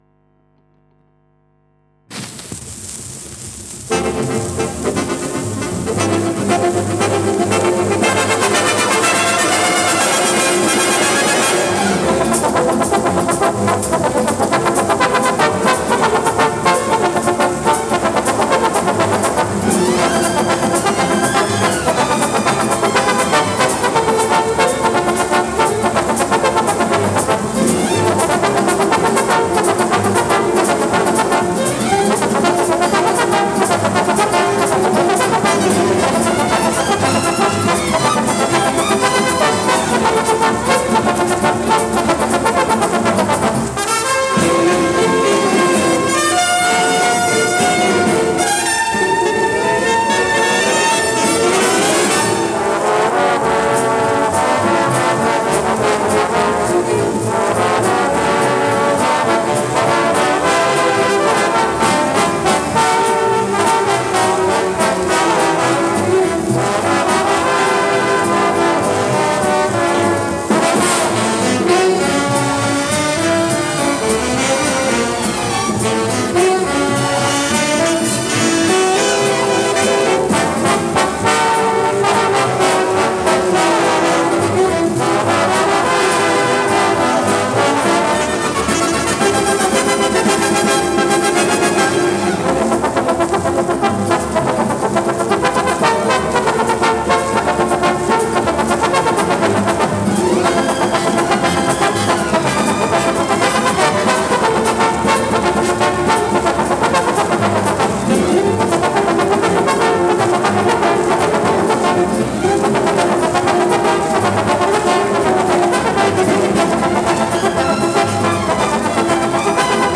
Квартет трамбонистов